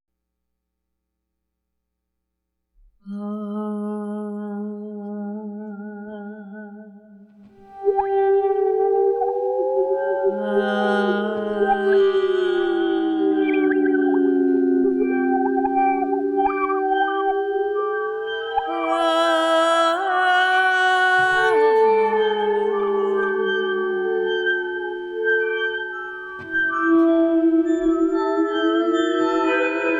Adventurous Electronic Excursions
Voice with Live Processing
Guitar and SuperCollider